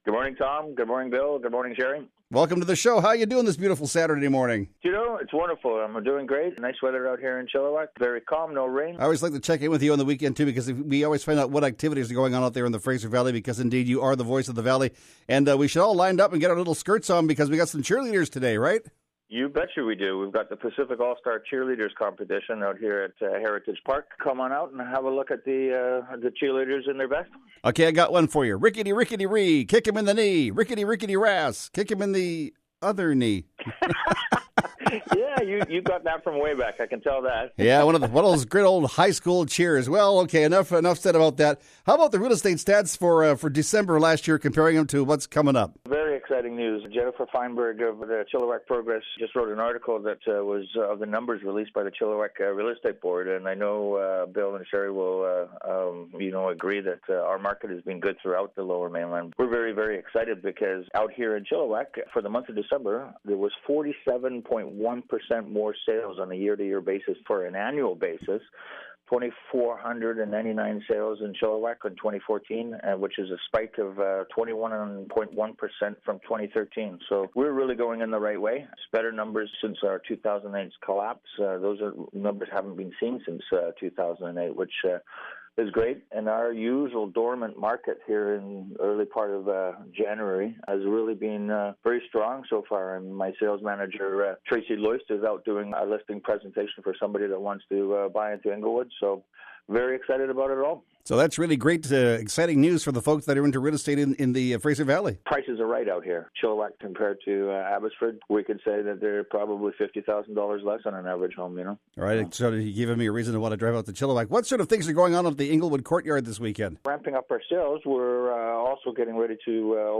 Real Estate Radio Host